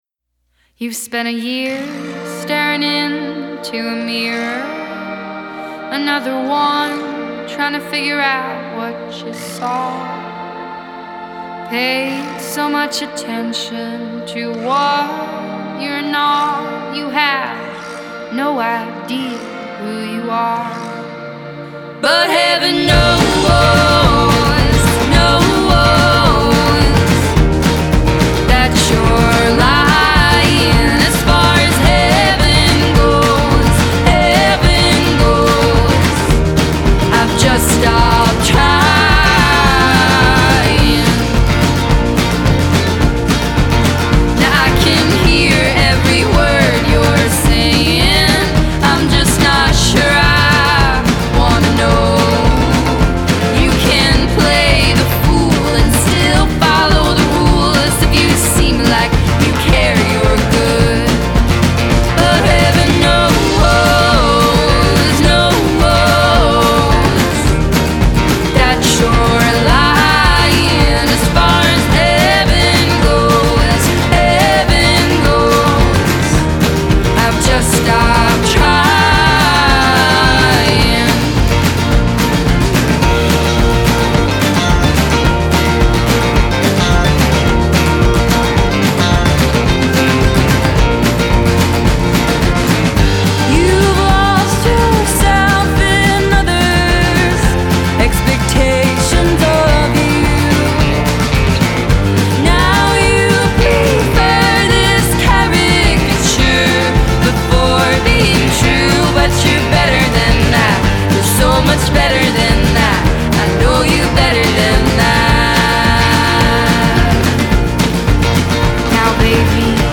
Genre: Indie Pop, Indie Folk